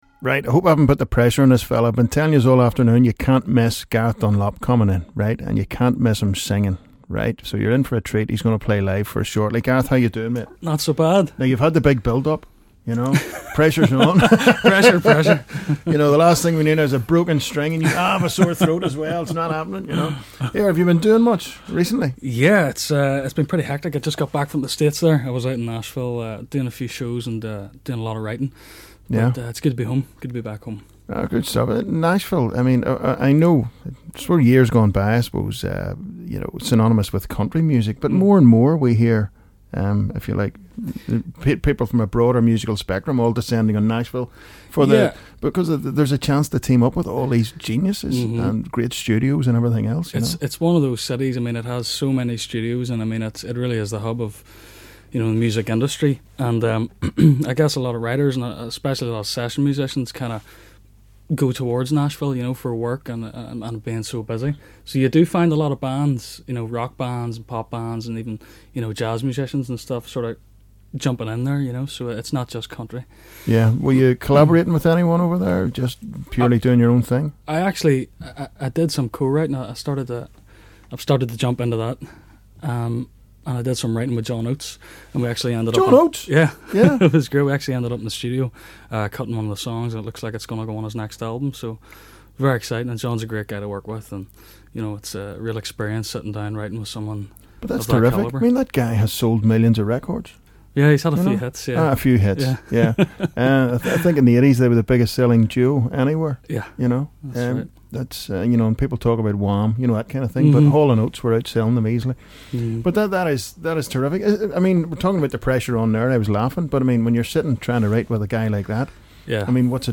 IN THE U105 STUDIO